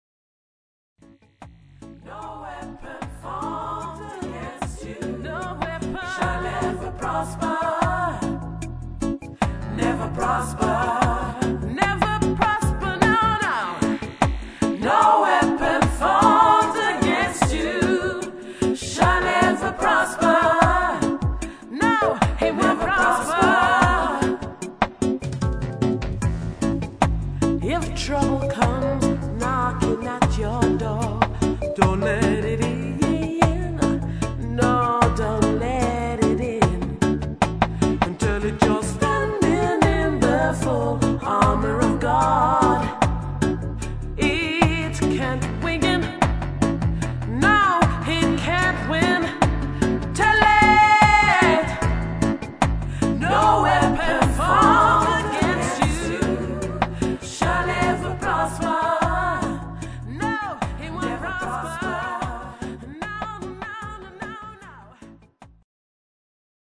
gospel reggae